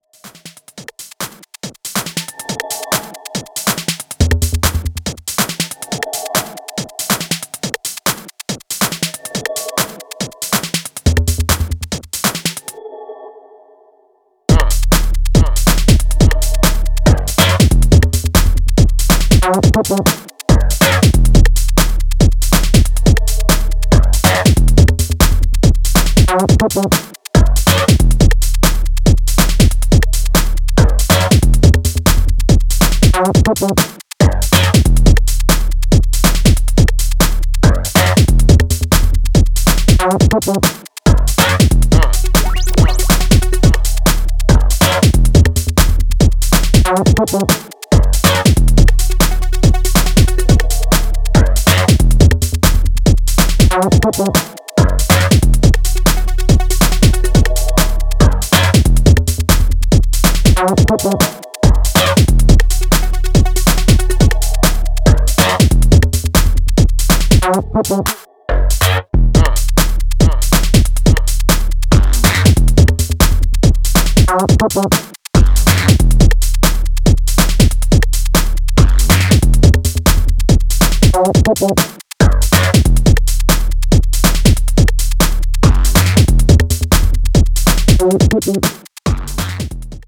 in house and techno mode